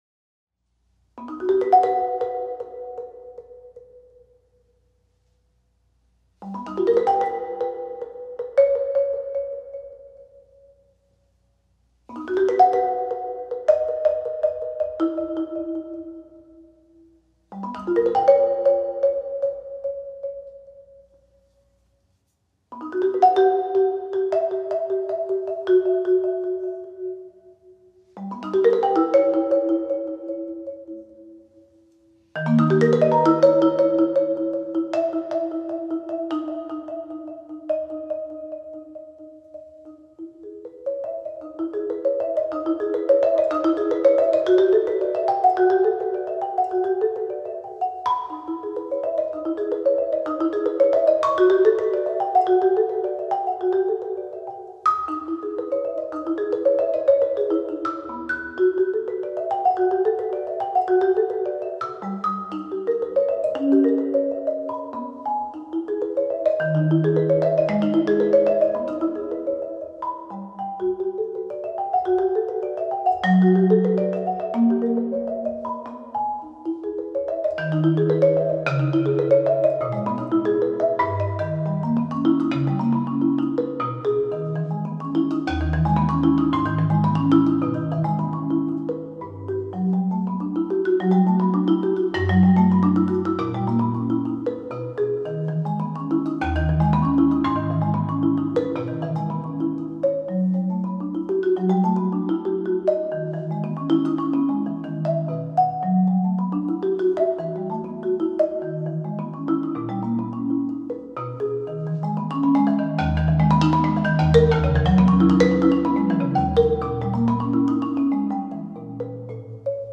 Voicing: Marimba Unaccompanied